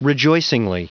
Prononciation du mot rejoicingly en anglais (fichier audio)
Prononciation du mot : rejoicingly